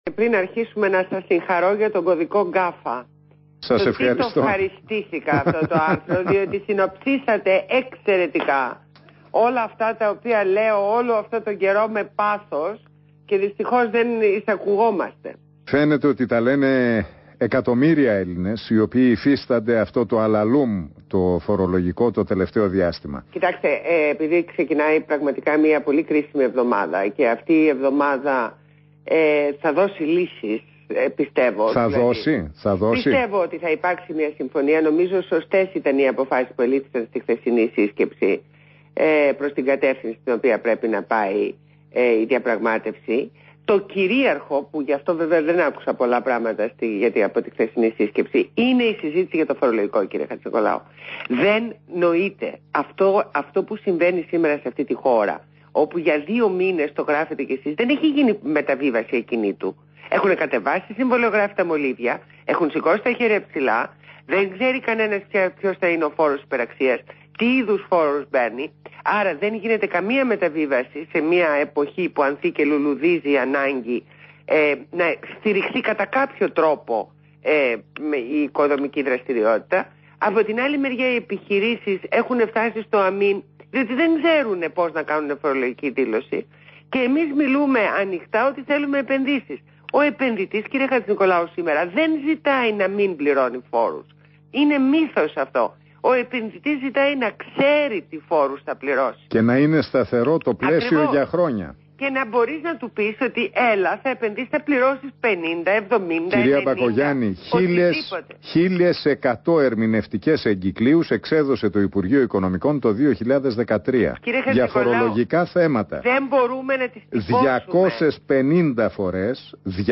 Συνέντευξη στο ραδιόφωνο του REAL fm
Ακούστε τη συνέντευξη στον δημοσιογράφο Νίκο Χατζηνικολάου.